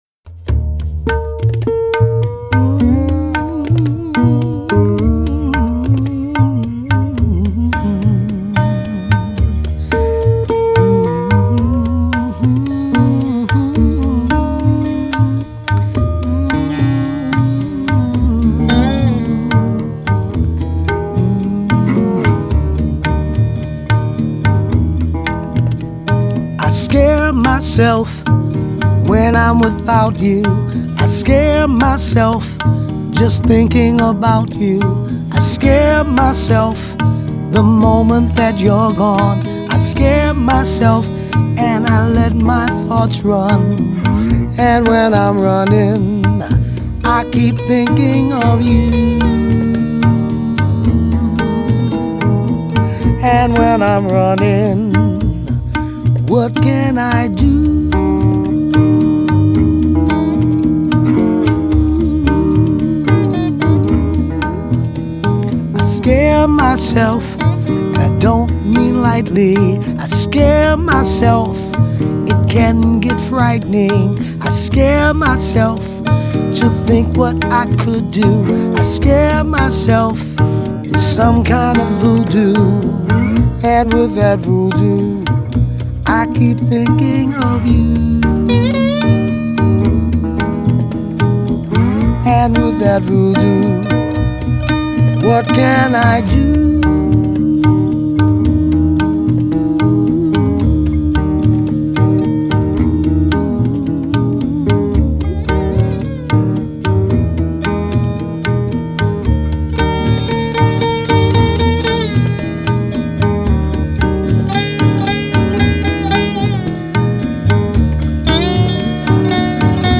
Vocals
Gadgie Resophonic Guitar
Satvik Veena
Tabla and Vocals